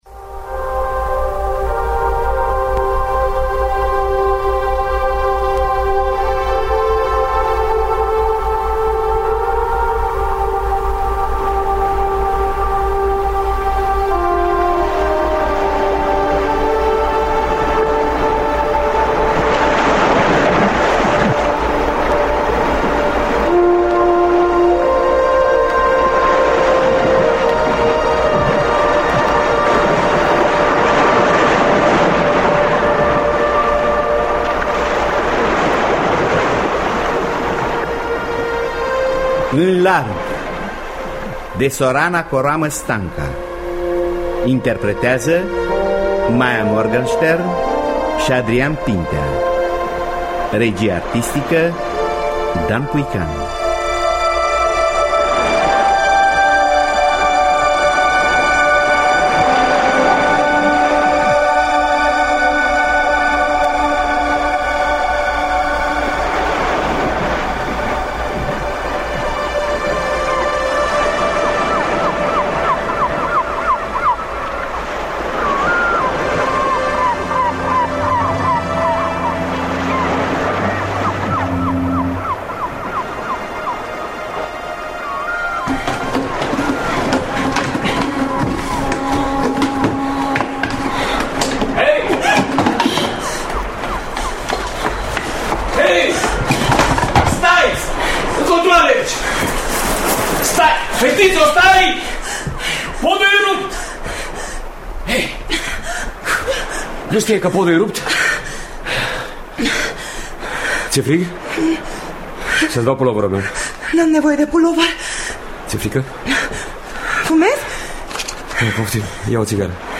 În distribuţie; Maia Morgenstern şi Adrian Pintea.